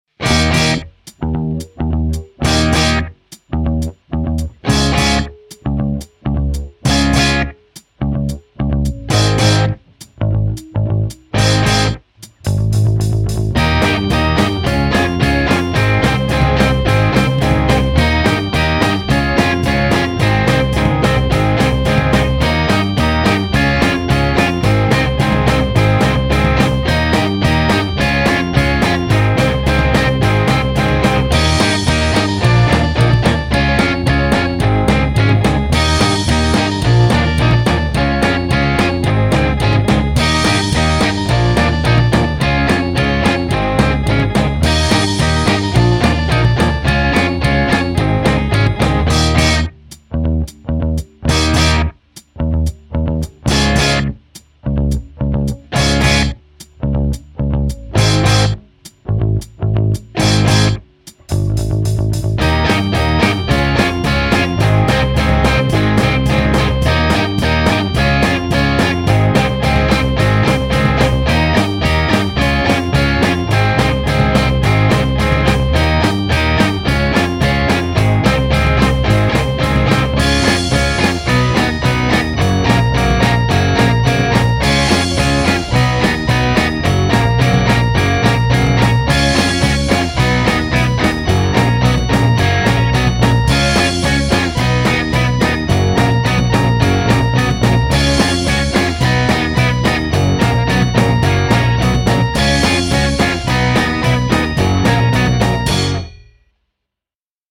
Žánr: Punk
Power pop/punk, Humenné, Slovensko.